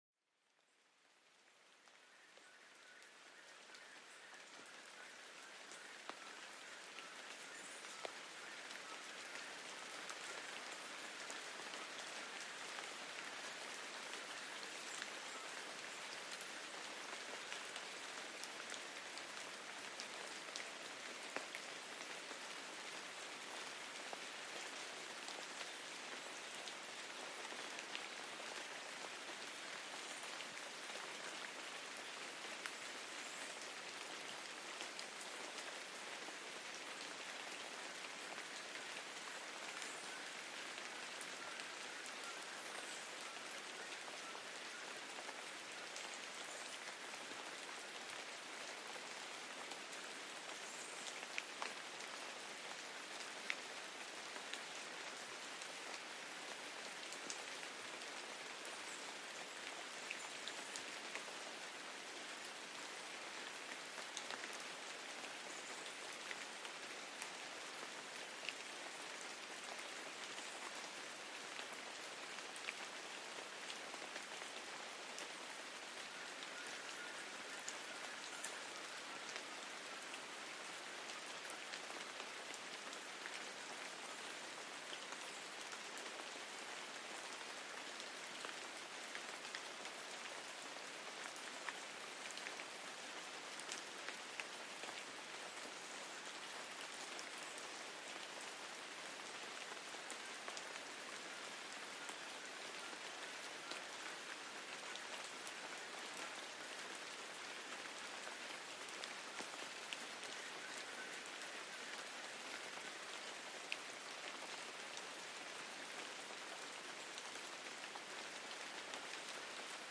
La suave melodía de la lluvia cayendo sobre las hojas en un bosque proporciona una calma instantánea. Déjate arrullar por estos sonidos naturales para una relajación profunda. Un episodio perfecto para relajarse y quedarse dormido.Este podcast te transporta al corazón de la naturaleza con grabaciones auténticas de diversos sonidos naturales.